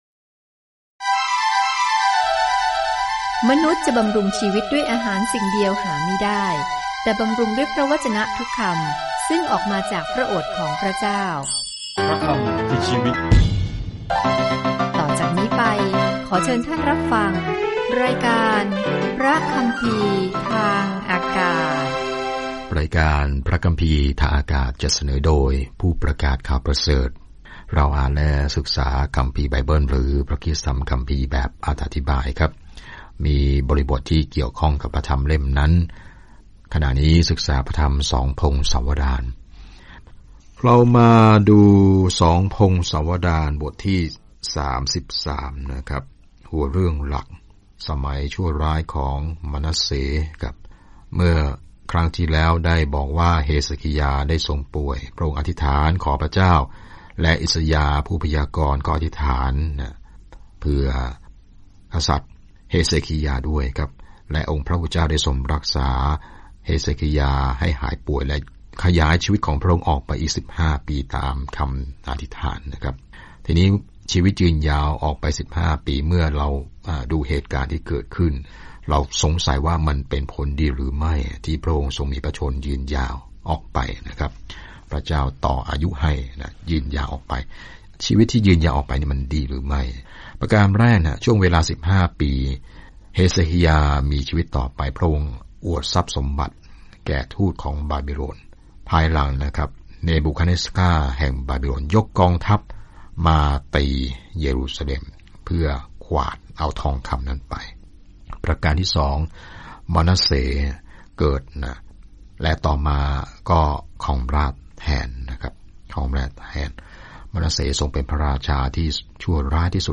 ใน 2 พงศาวดาร เราได้รับมุมมองที่แตกต่างออกไปเกี่ยวกับเรื่องราวที่เราได้ยินเกี่ยวกับกษัตริย์และผู้เผยพระวจนะในอดีตของอิสราเอล เดินทางทุกวันผ่าน 2 พงศาวดารในขณะที่คุณฟังการศึกษาด้วยเสียงและอ่านข้อที่เลือกจากพระวจนะของพระเจ้า